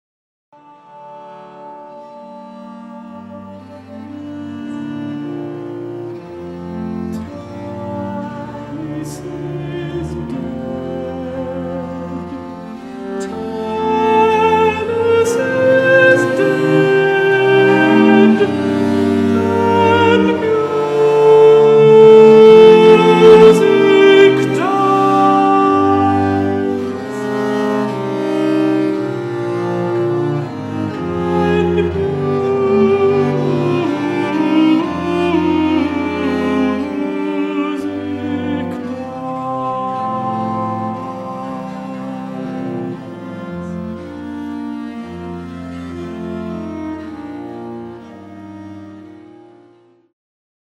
a lament on the death of his mentor